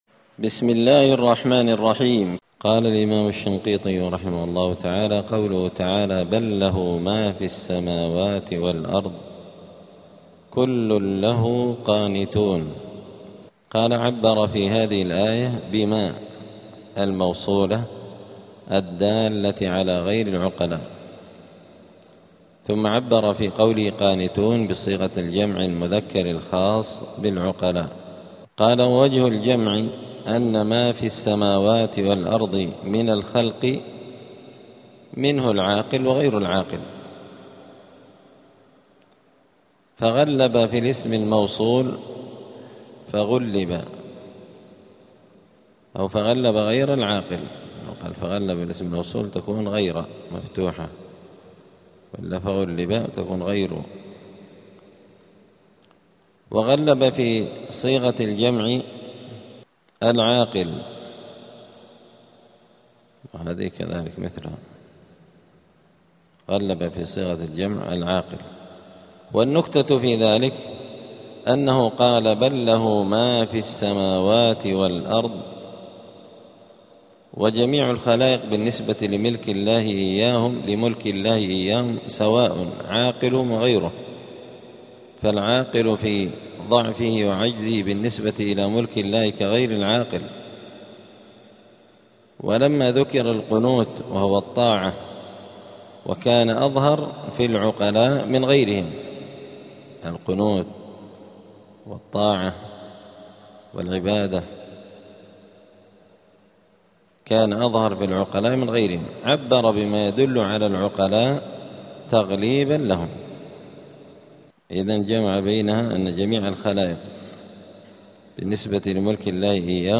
*الدرس التاسع (9) {سورة البقرة}.*